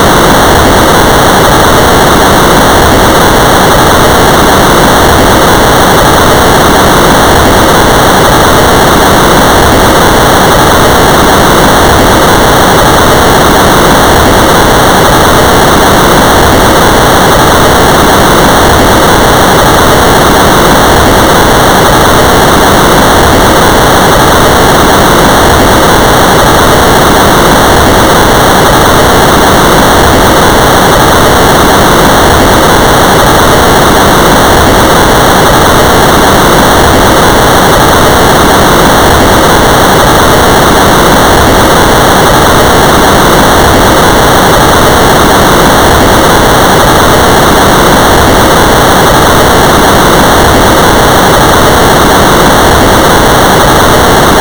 engine.wav